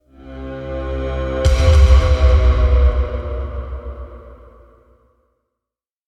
06246 ghostly hit
blaze descending drum fail ghost haunted hit horror sound effect free sound royalty free Sound Effects